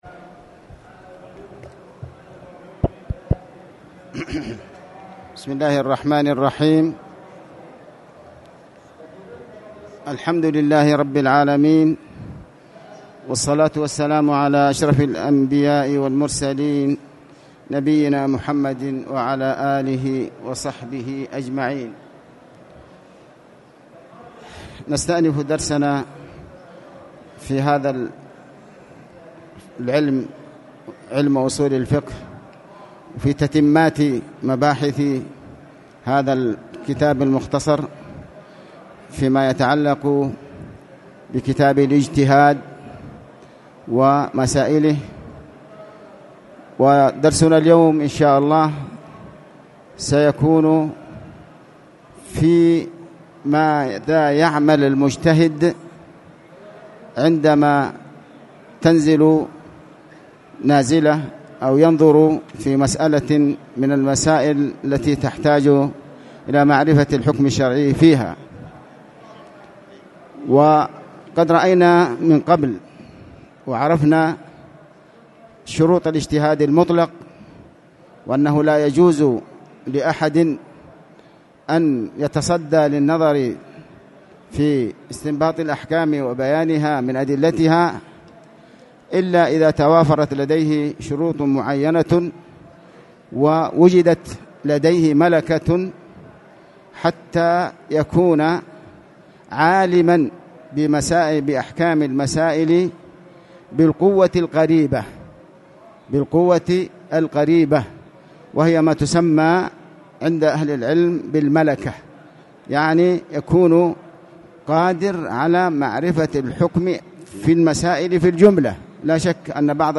تاريخ النشر ٢٥ شوال ١٤٣٨ هـ المكان: المسجد الحرام الشيخ: علي بن عباس الحكمي علي بن عباس الحكمي الإجتهاد